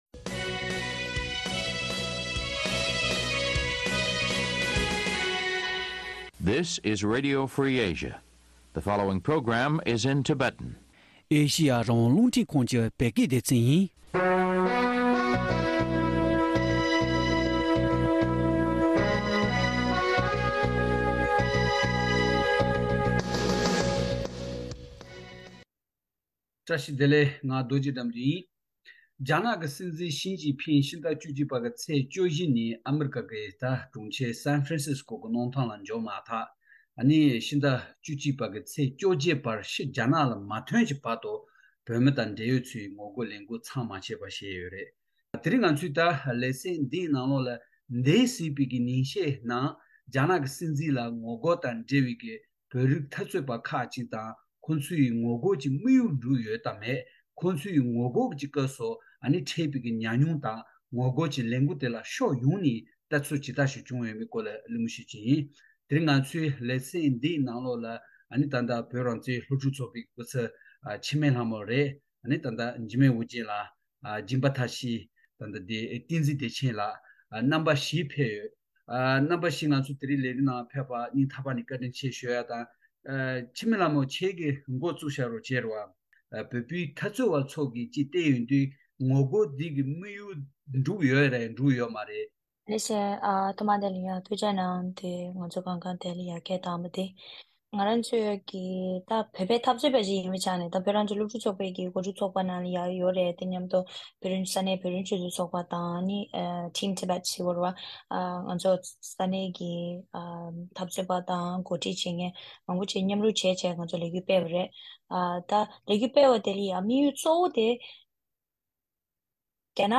བགྲོ་གླེང་བྱས་པ་ཉན་རོགས་ཞུ།